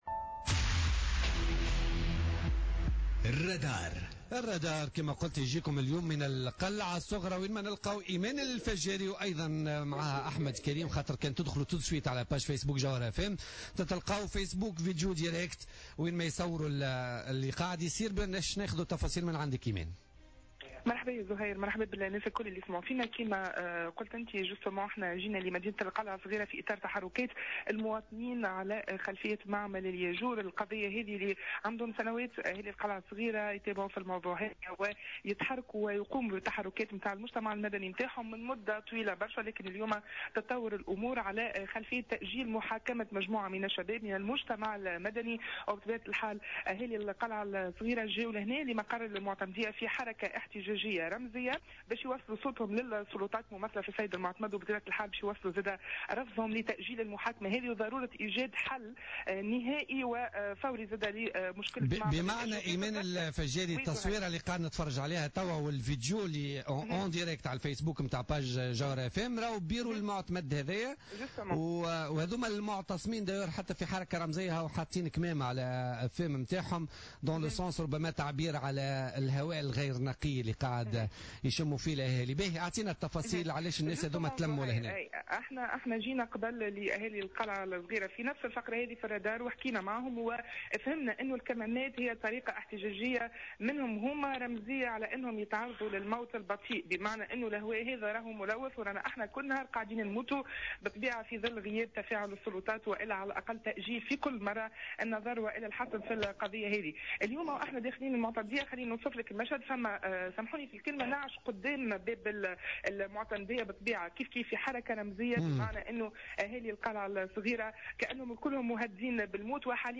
تحول فريق "الرادار" اليوم الاثنين إلى معتمدية القلعة الصغرى أين ينفذ الأهالي اعتصام مفتوح في مقر المعتمد، تنديدا بتأجيل محاكمة مجموعة من شباب المجتمع المدني، وللمطالبة بحل نهائي لمصنع الآجر.